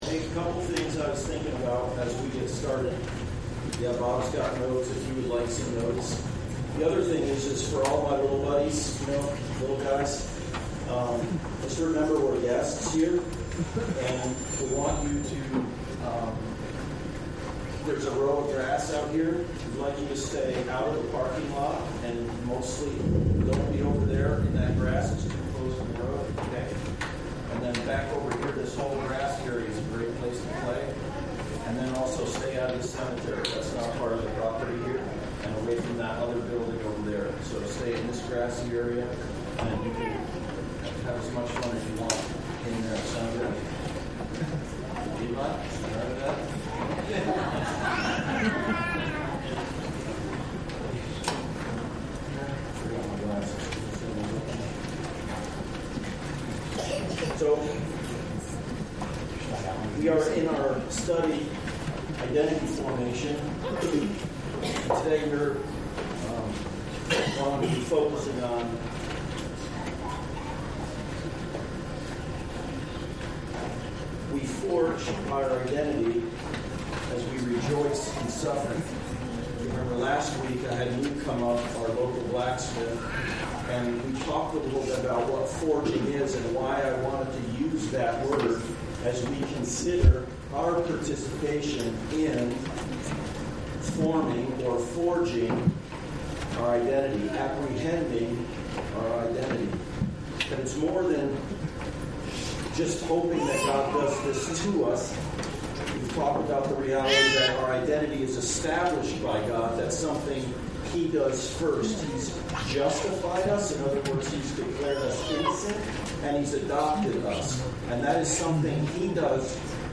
Identity Formation Passage: Romans 5:1-5 Service Type: Sunday Service « Identity Formation